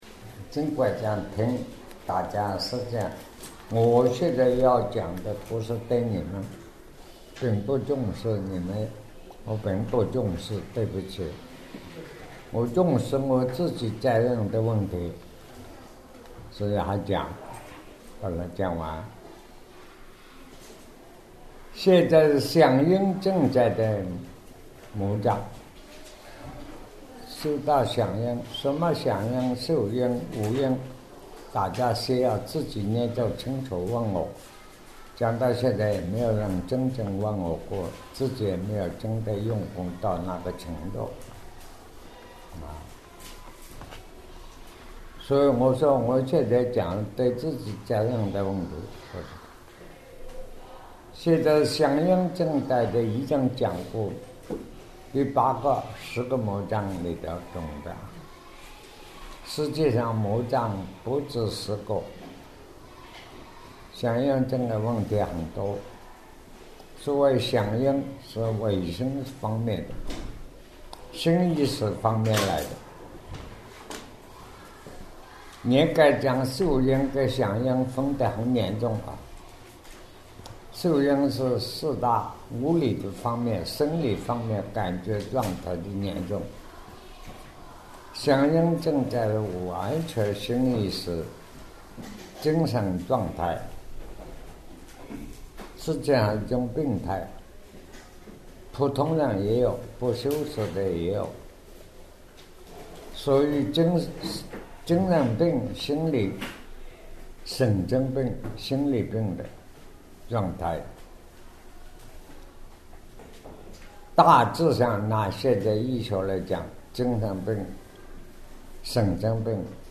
南怀瑾先生2009年讲楞严经147讲 卷九 五阴解脱 想阴区宇魔境2-5